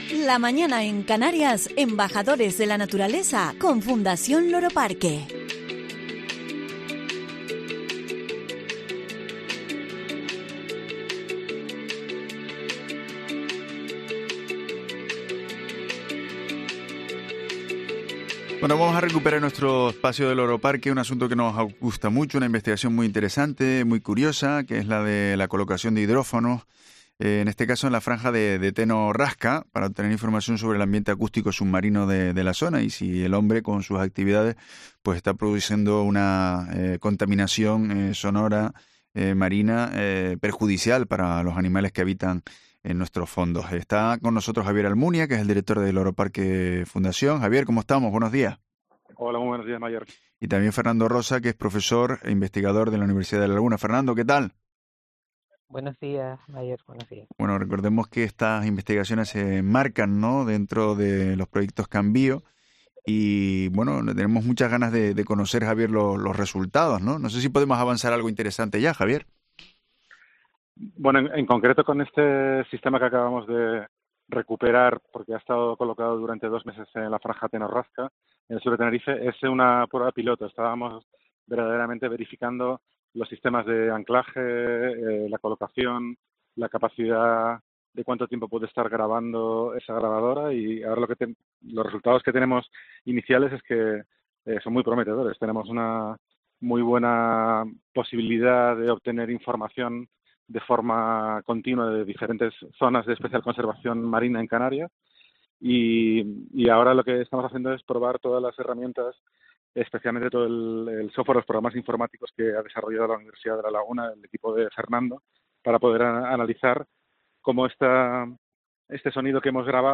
En declaraciones en Herrera en Cope Canarias